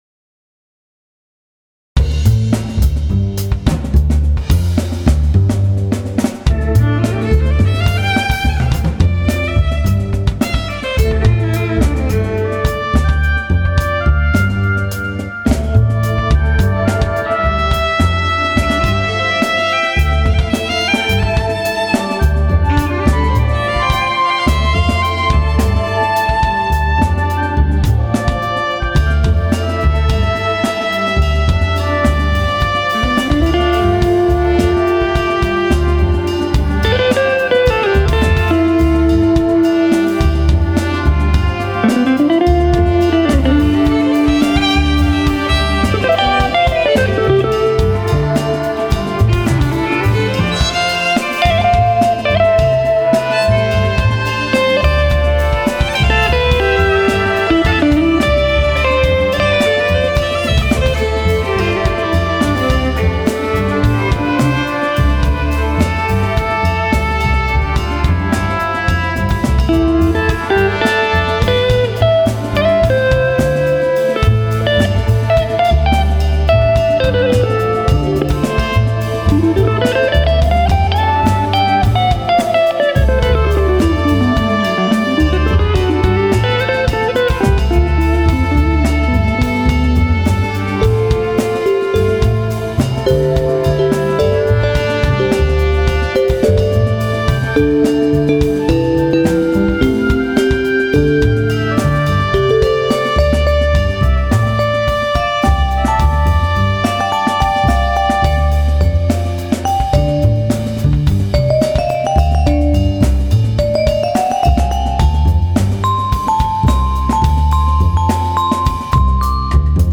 Synth- Organ, Piano, Pads
Violin
Guitar, Electro Acoustics
Bass, Drums, Percussion, Vibraphone